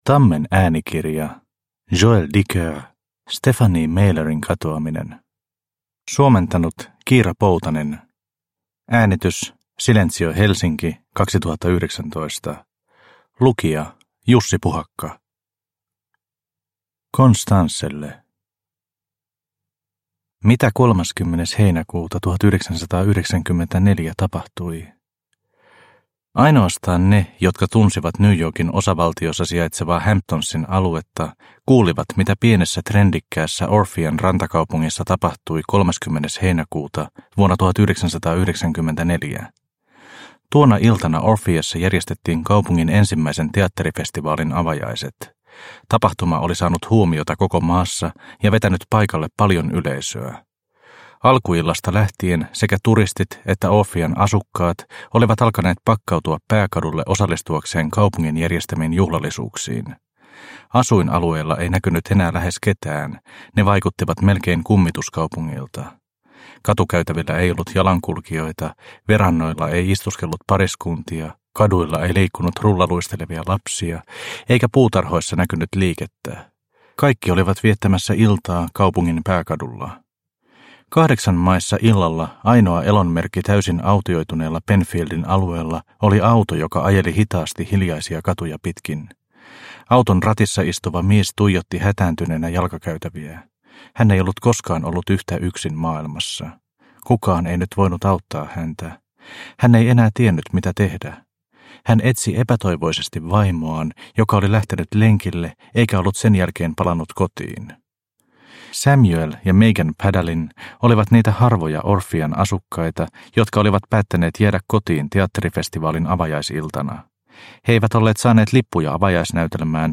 Stephanie Mailerin katoaminen – Ljudbok – Laddas ner